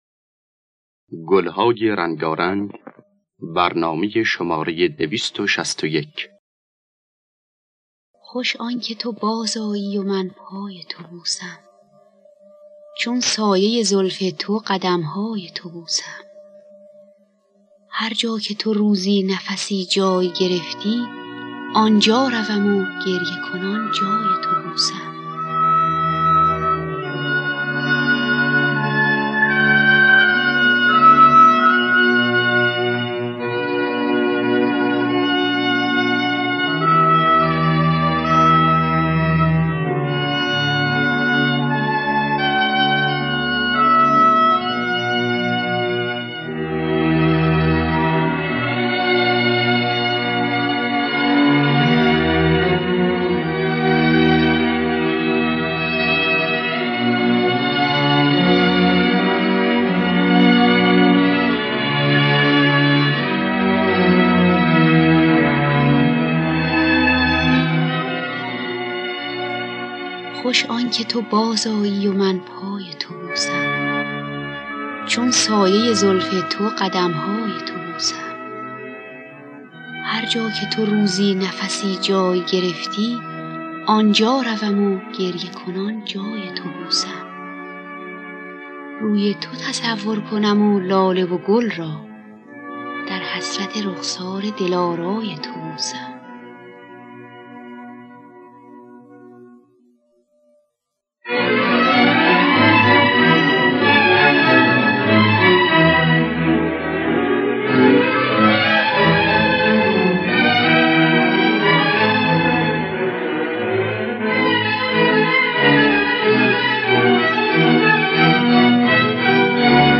خوانندگان: مرضیه نوازندگان: روح‌الله خالقی علی تجویدی